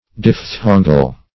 Search Result for " diphthongal" : The Collaborative International Dictionary of English v.0.48: Diphthongal \Diph*thon"gal\ (?; 115), a. Relating or belonging to a diphthong; having the nature of a diphthong.